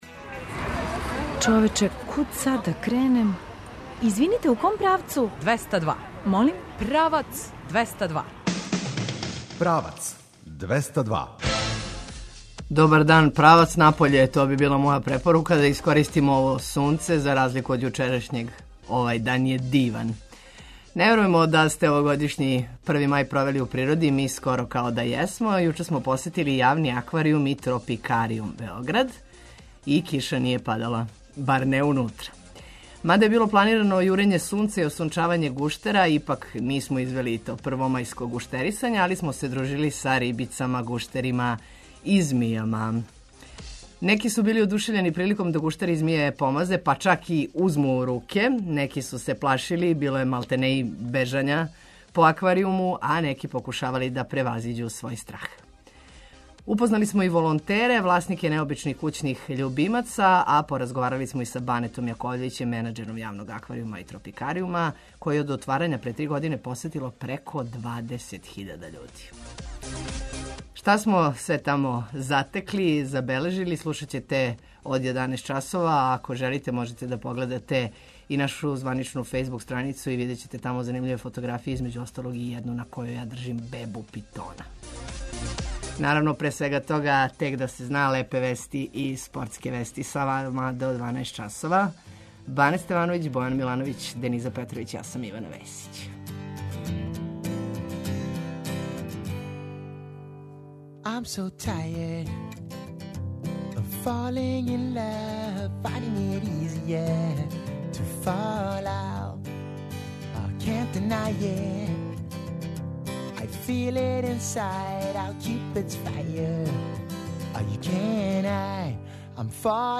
Посетили смо Јавни акваријум и тропикаријум Београд и киша није падала, бар унутра.